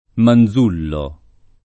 [ man z2 llo ]